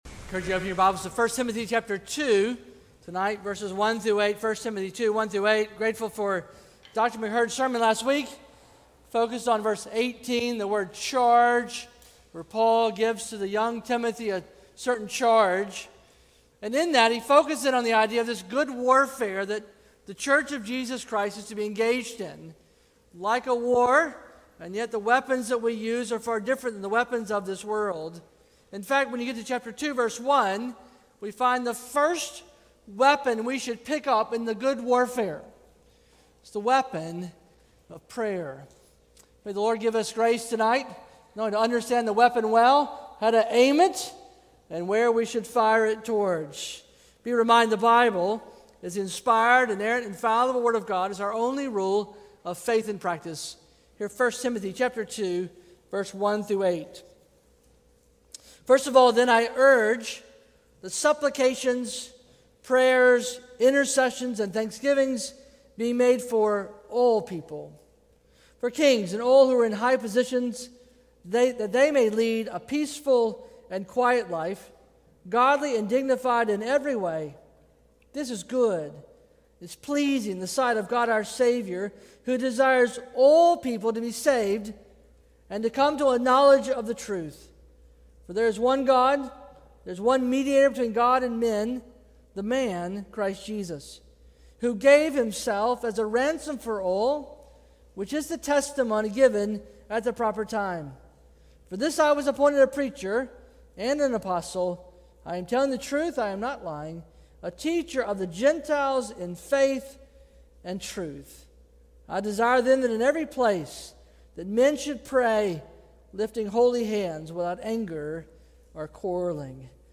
A sermon from the series "Passing on the Faith."